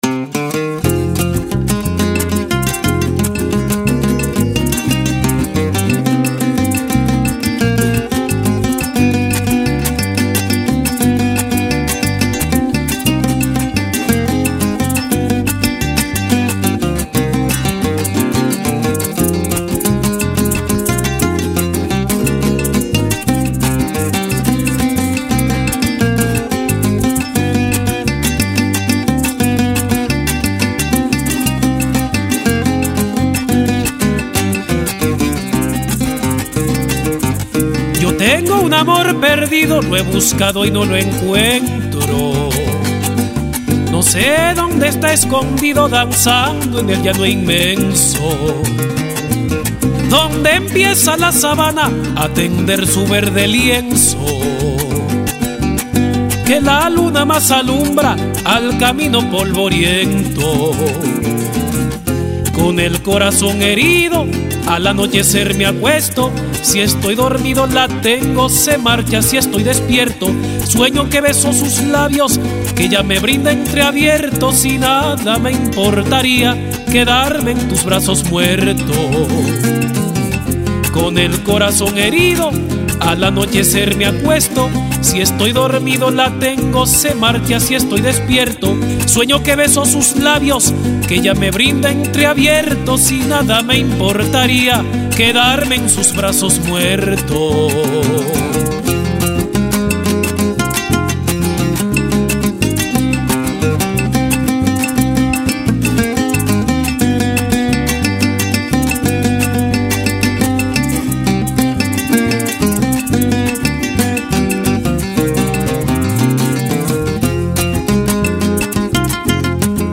Ritmo: Pasaje.